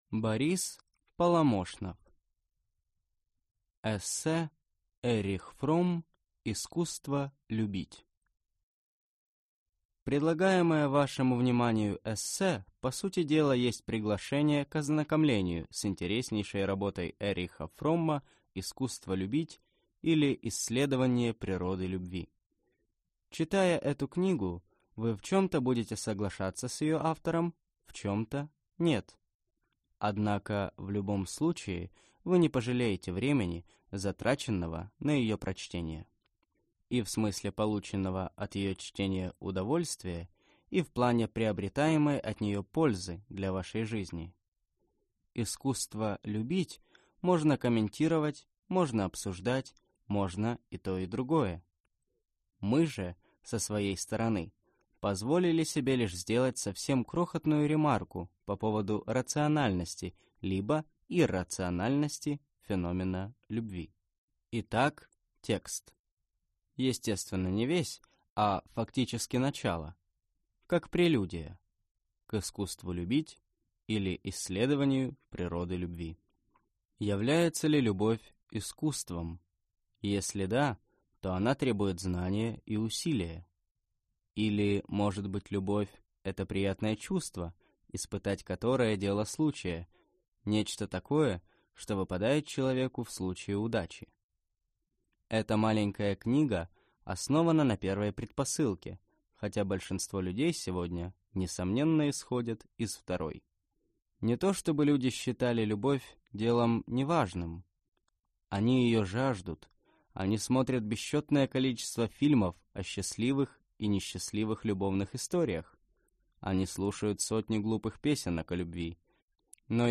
Аудиокнига Фромм: «Искусство любить…»